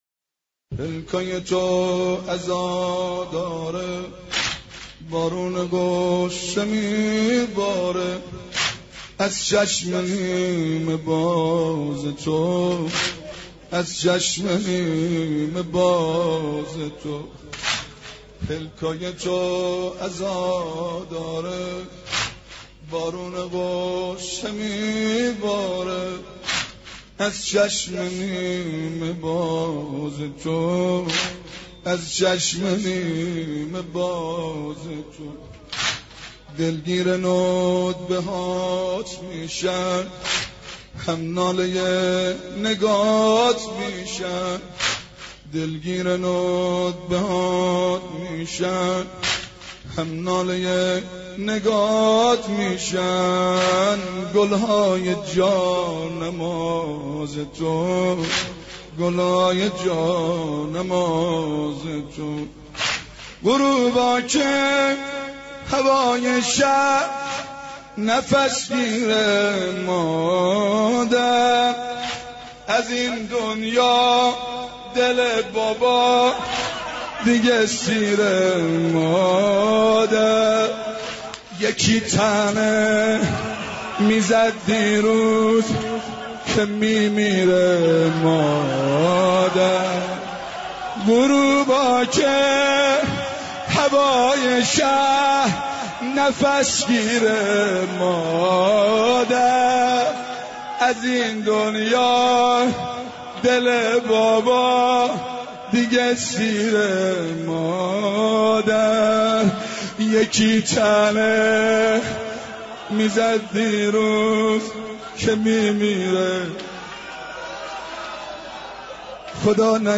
مداحی و نوحه
نوحه خوانی و سینه زنی